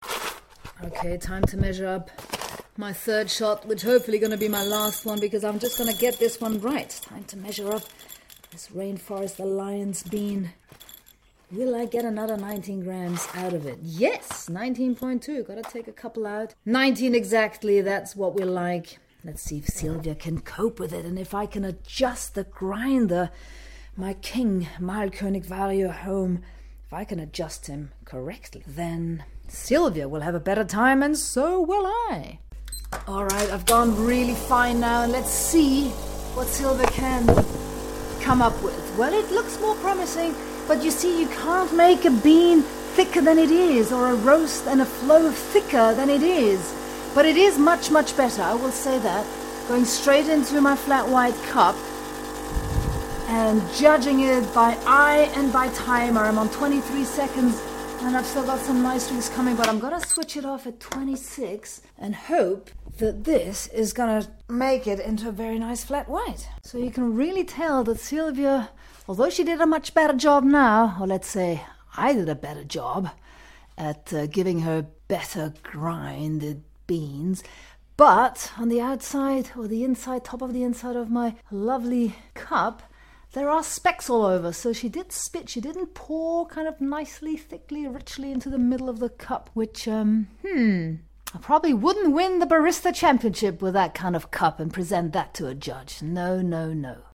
3rd extraction straight into my flat white cup ... still scope for improvement on the barista side of things .. that's for sure!